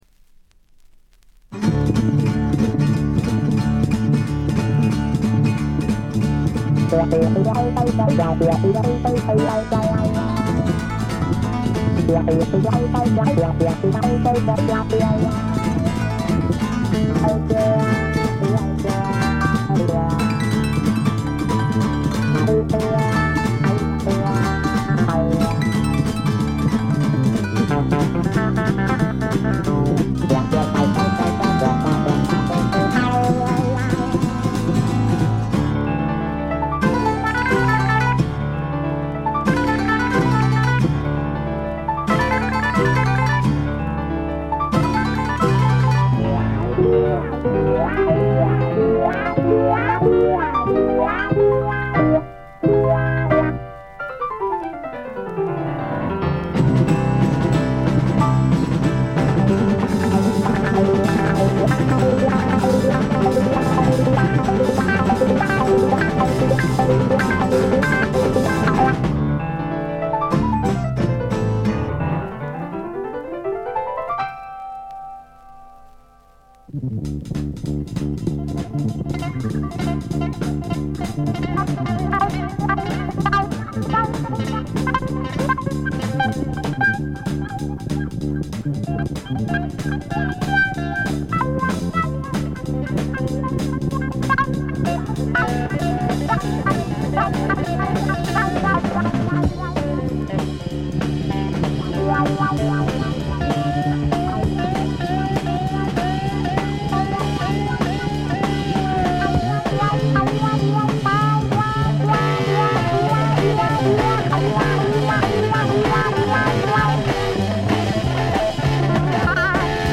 わずかなノイズ感のみ。
試聴曲は現品からの取り込み音源です。
Electric Guitar, Acoustic Guitar, Piano, Spinet
Soprano Saxophone